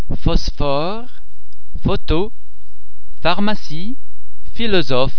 Please be mindful of the fact that all the French sounds are produced with greater facial, throat and other phonatory muscle tension than any English sound.
The French [f] and [ph] are normally pronounced [f] as in the English words flower, full, photo etc.
f_phosphore.mp3